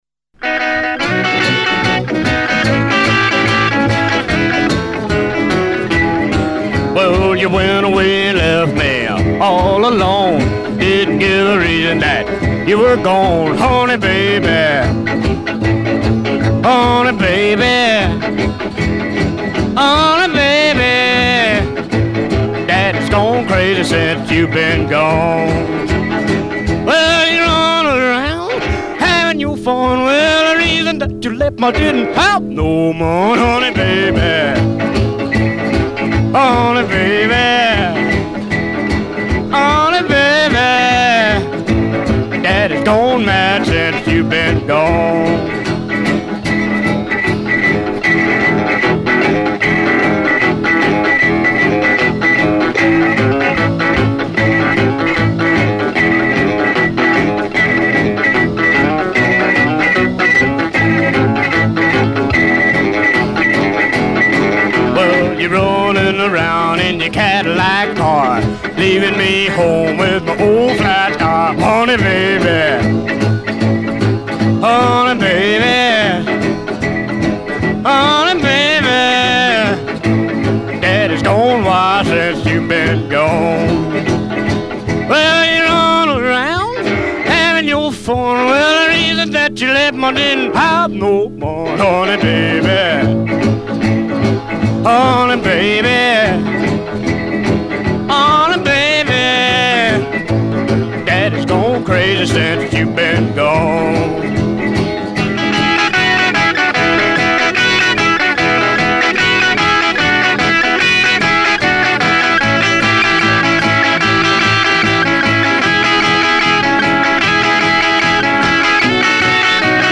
Looking for some rockabilly I hadn't heard